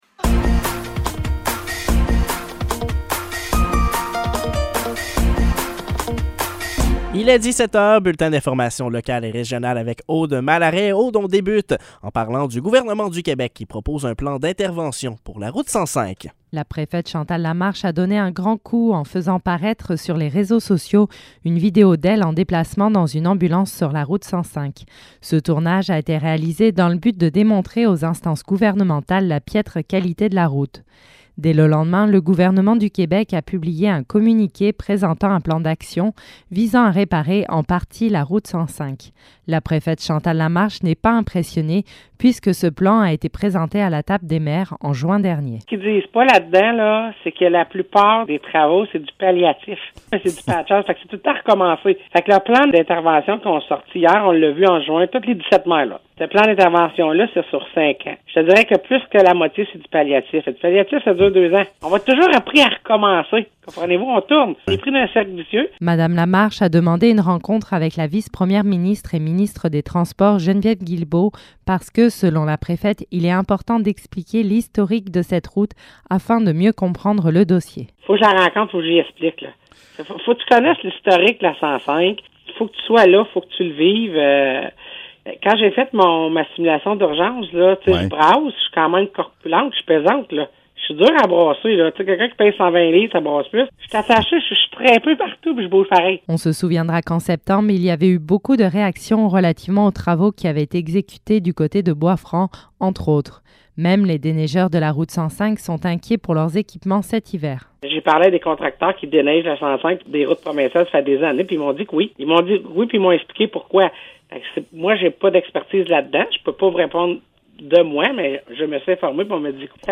Nouvelles locales - 15 décembre 2022 - 17 h